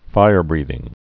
(fīrbrēthĭng)